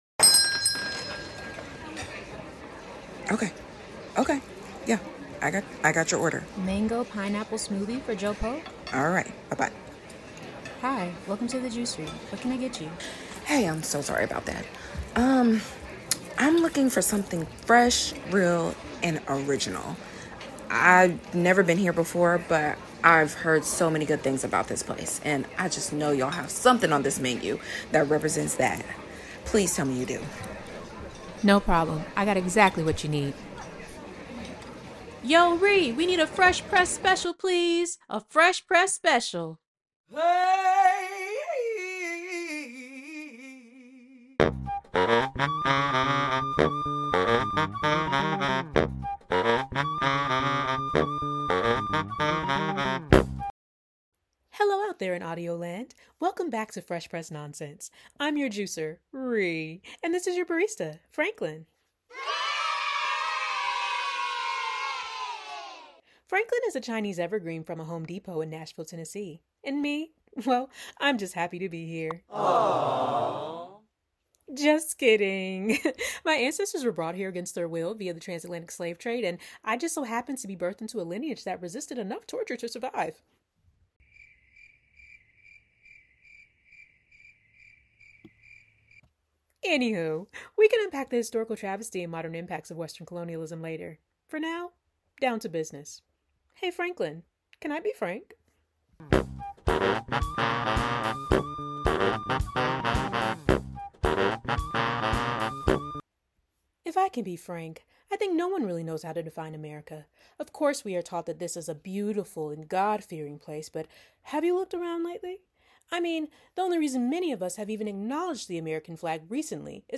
Skit 1: Divide and Conquer-ish Skit 2: You’re Not You When…